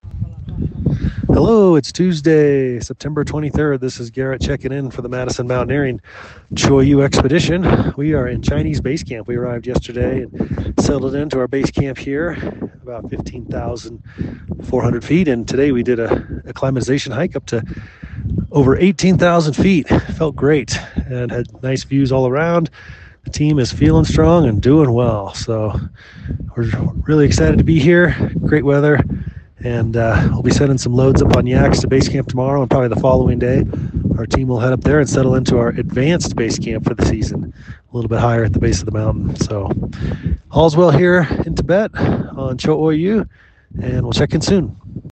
Chinese Basecamp